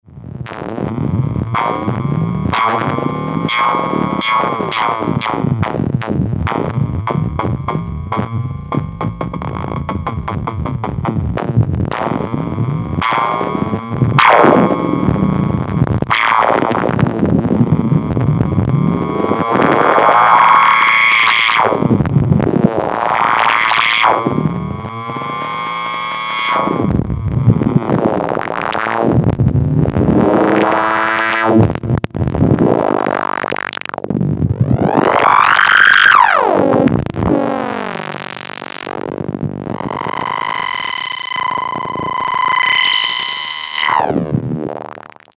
The sound could be described as very vintage, full bodied, and animated.
Unease_P9700s_Modulated.mp3